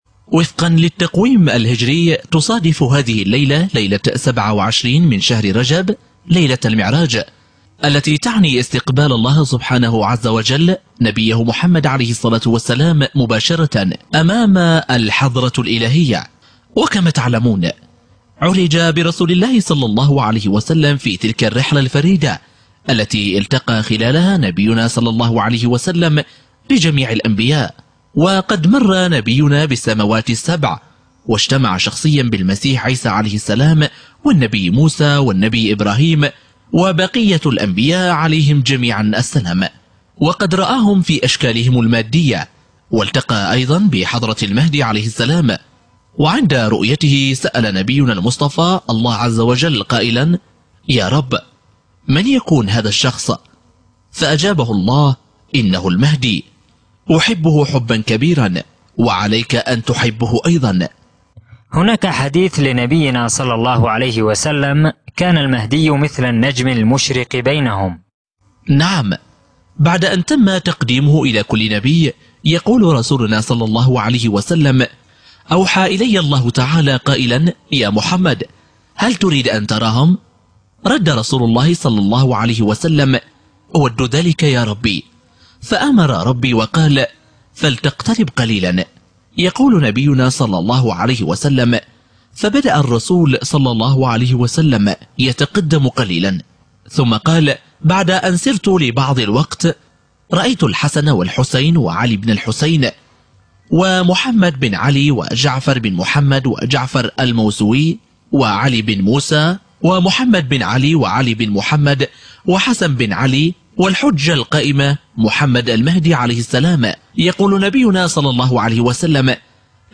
مقتطفات من مقابلة للسيد عدنان أوكتار في بث حي على قناة A9TV بتاريخ 3 مايو عدنان أوكتار: وفقا للتقويم الهجري، تصادف هذه الليلة، ليلة 27 من شهر ...